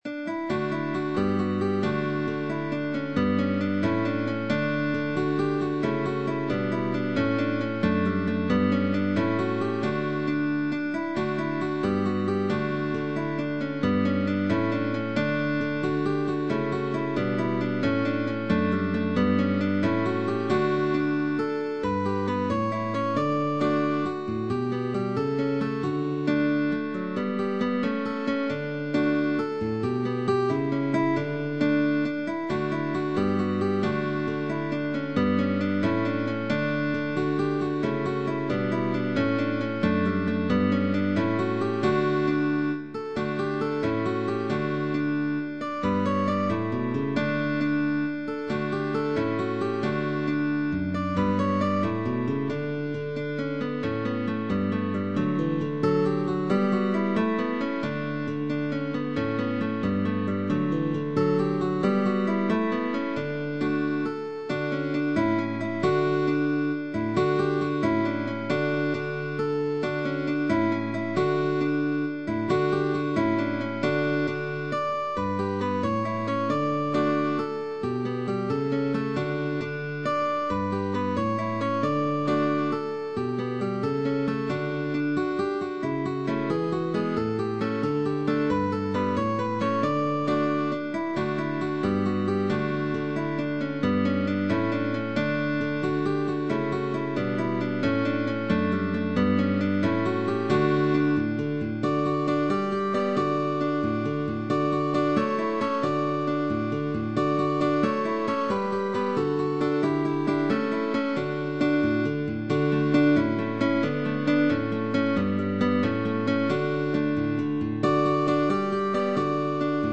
DÚO DE GUITARRAS
Aquí se presenta en un arreglo para Dúo de guitarras.